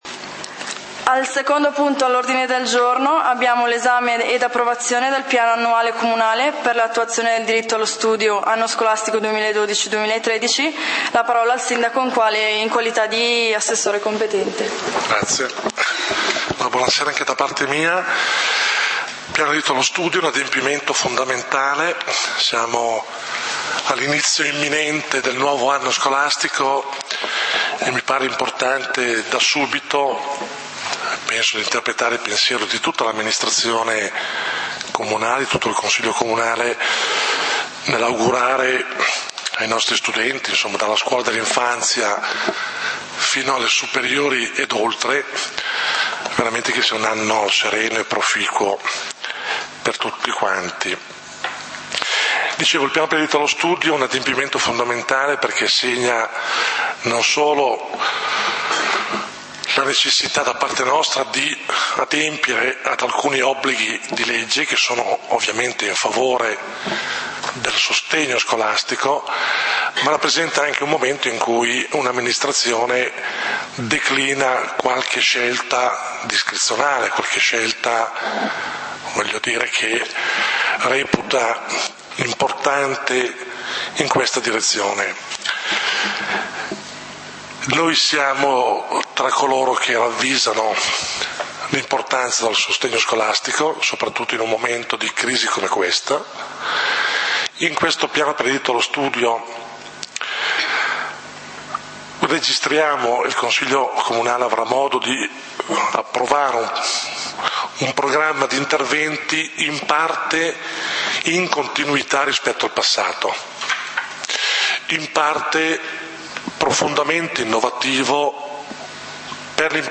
Punti del consiglio comunale di Valdidentro del 10 Settembre 2012